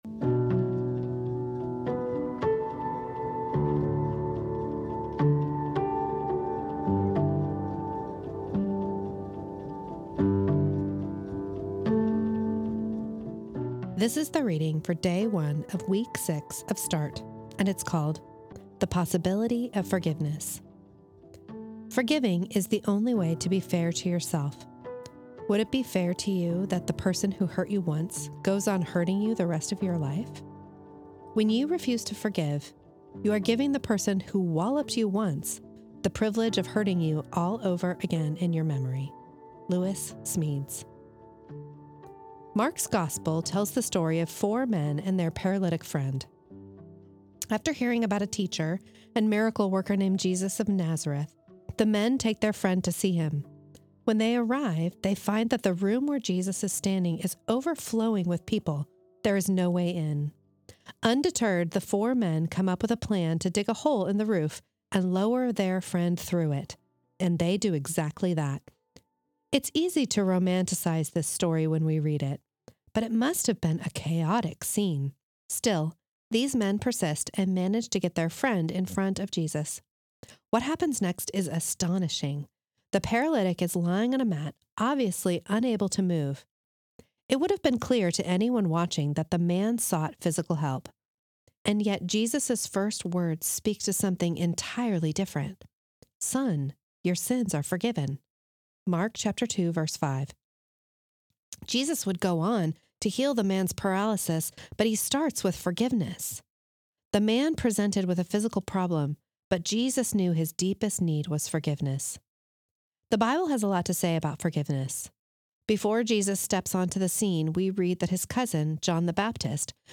This is the audio recording of the first reading of week eight of Start, entitled The Possibility of Forgiveness.